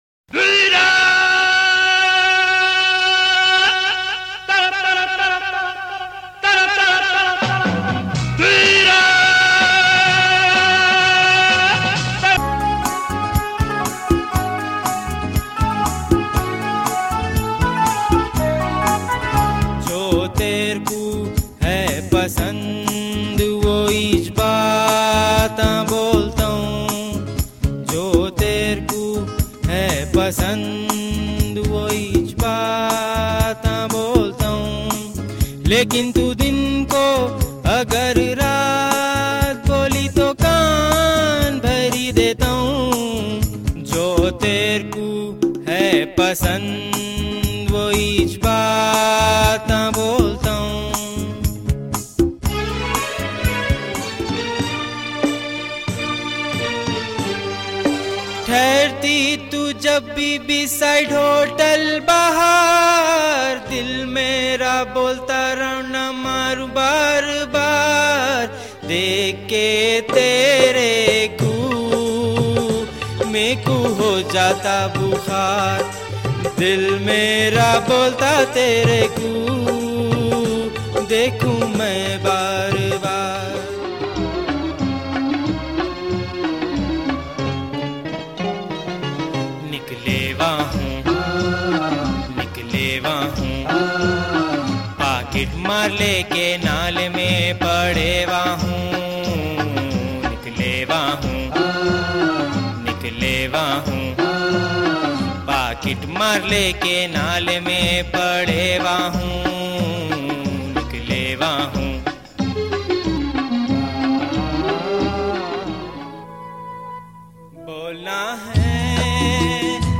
HomeMp3 Audio Songs > Others > Funny Mp3 Songs